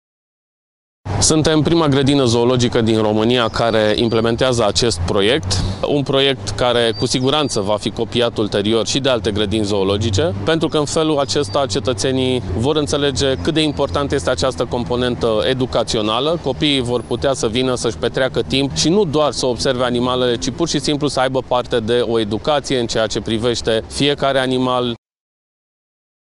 Viceprimarul municipiului Brașov, Sebastian Rusu: